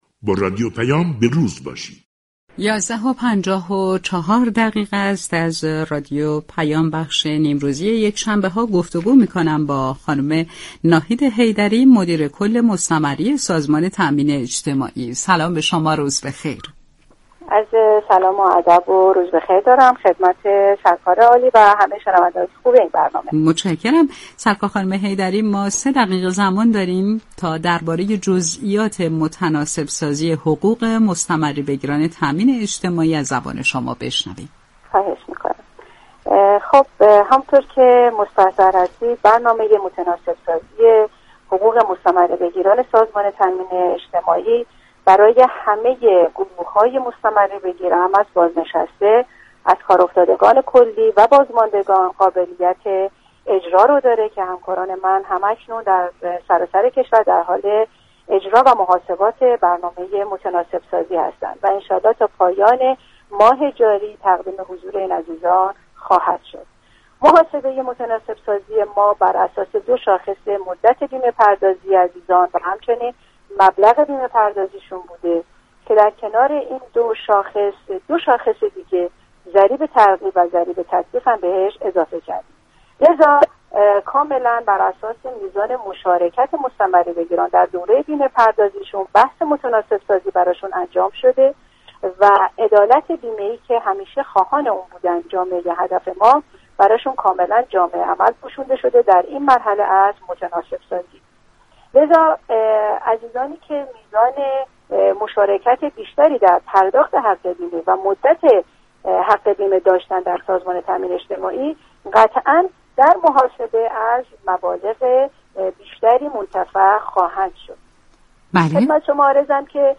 كبیری، معاون امور تعاون وزارت تعاون، كار و رفاه اجتماعی ، در گفتگو با رادیو پیام